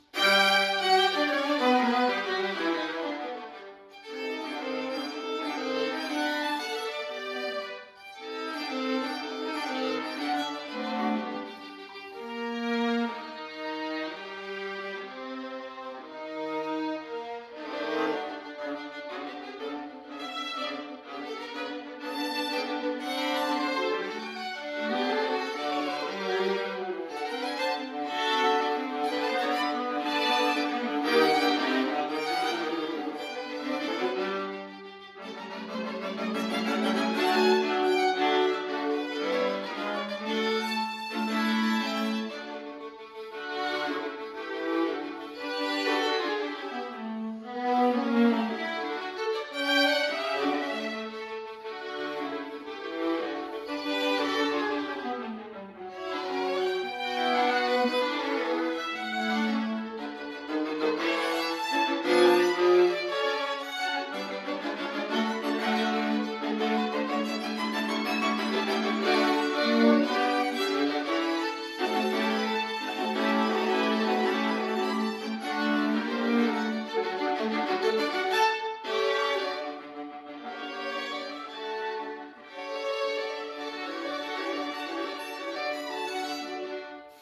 For 2 Violins and Viola or 2 Violins and Cello